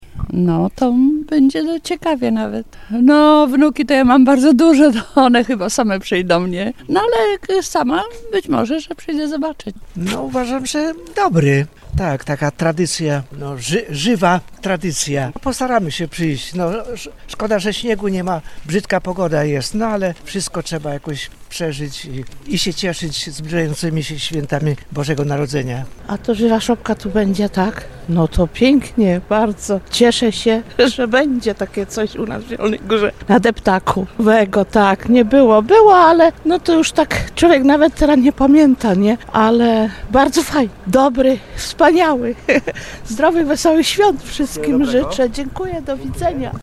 Spytaliśmy zielonogórzan jak im podoba się pomysł z szopką i żywymi zwierzętami na deptaku: